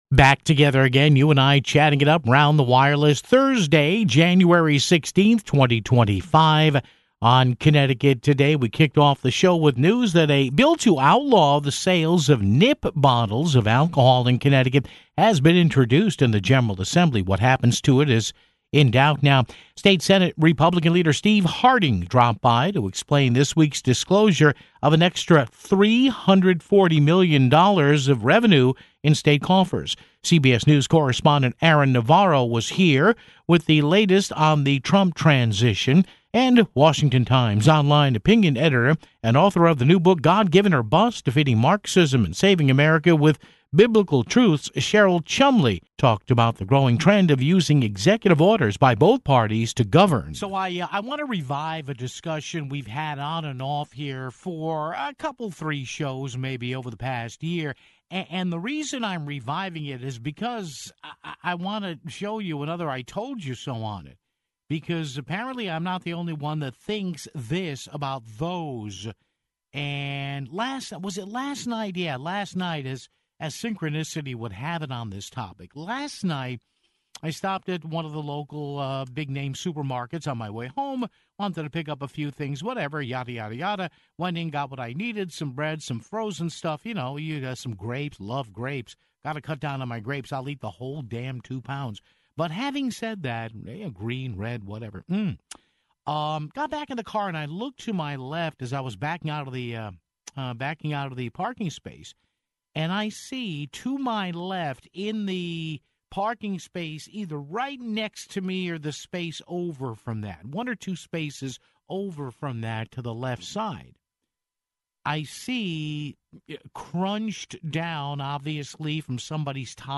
State Senate GOP leader Steve Harding explained this week's disclosure of an extra $340 million dollars of revenue in state coffers (14:20).